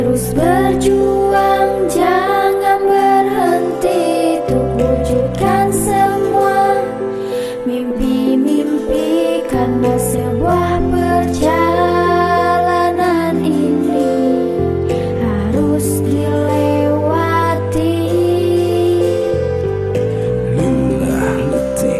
Magic com COSMOS CRJ 3232 sound effects free download
Magic com COSMOS CRJ 3232 (2Liter)